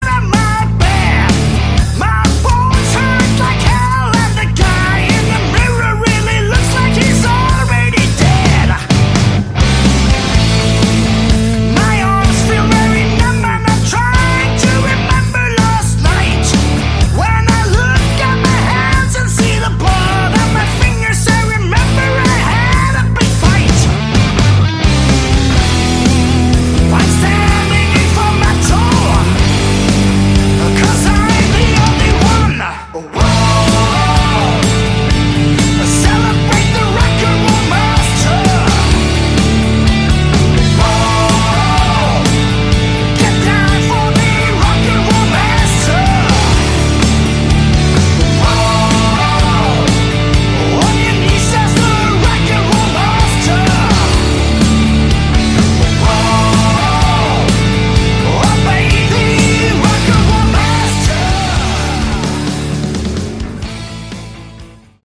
Category: Hard Rock
Vocals, Lead Guitar
Vocals, rhythm guitar
Drums
Vocals, Bass